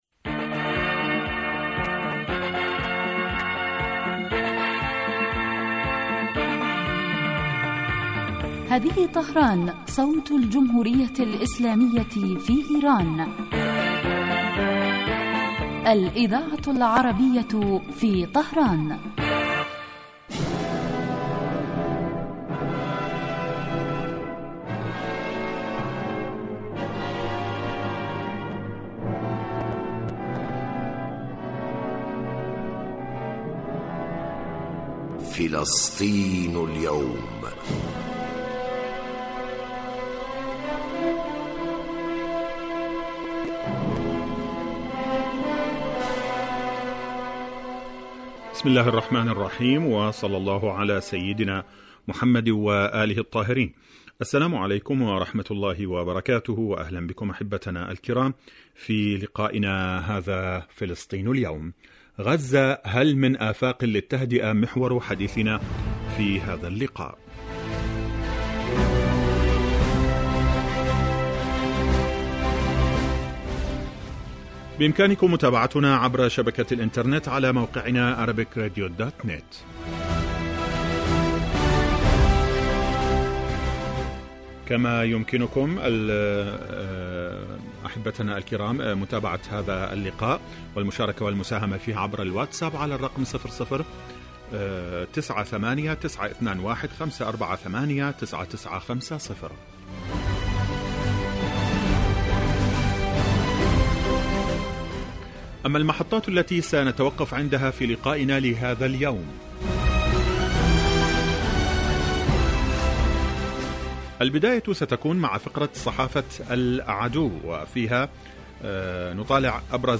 فلسطين اليوم برنامج يتناول تطورات الساحة الفلسطينية على كافة الصعد من خلال تقارير المراسلين واستضافة الخبراء في الشأن الفلسطيني